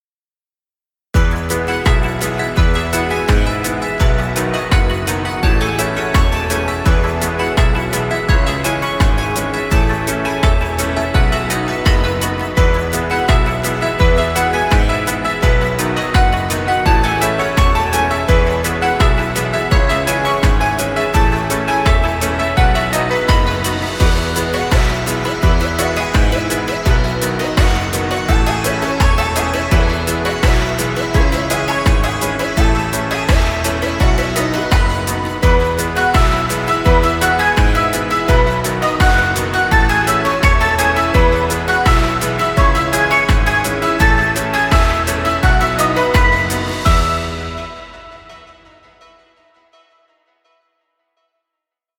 Childrens fun music. Background music Royalty Free.
Stock Music.